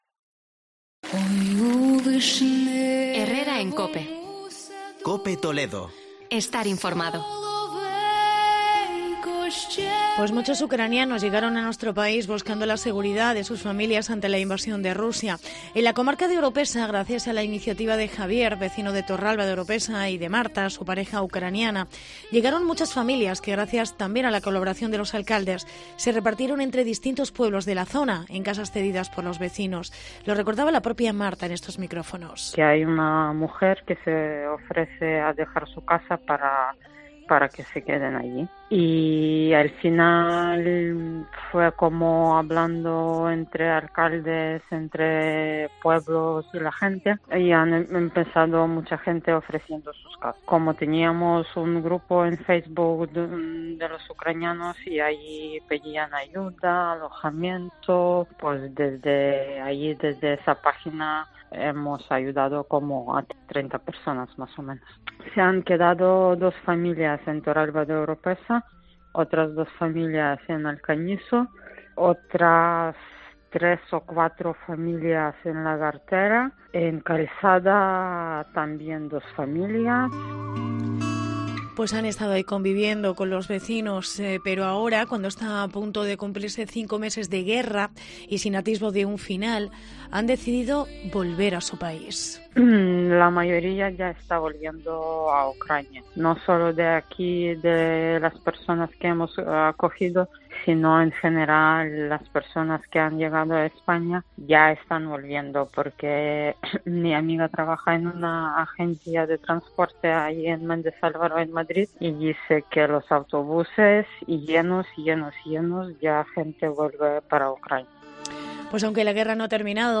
Reportaje vuelta de ucranianos a su país